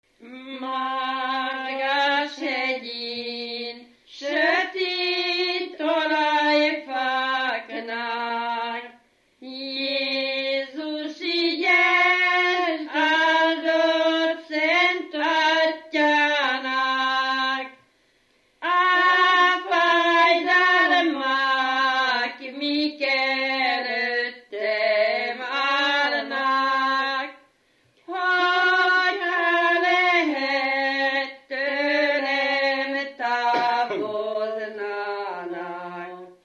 Felföld - Nyitra vm. - Menyhe
ének
Stílus: 9. Emelkedő nagyambitusú dallamok
Kadencia: 4 (5) 5 1